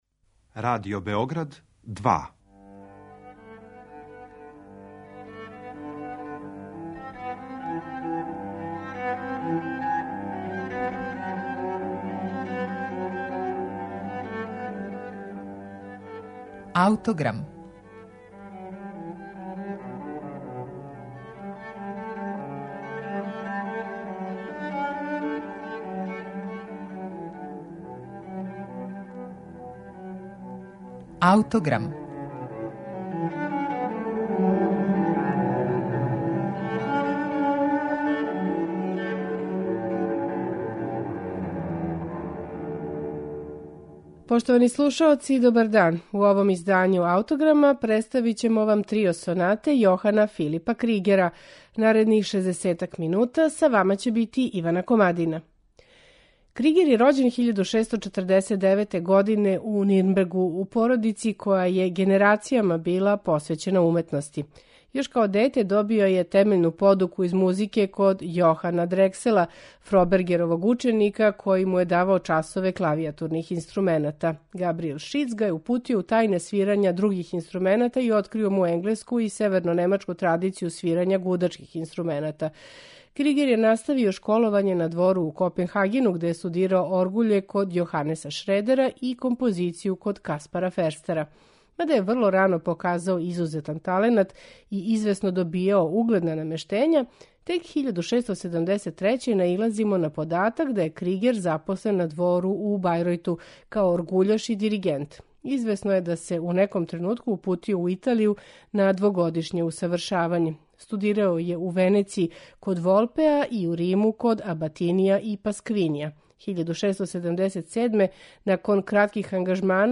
Кригерова Збирка соната за две виолине и басо континуо